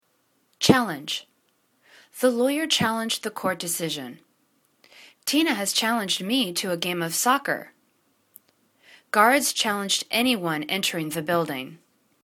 challenge     /'chalindg/    v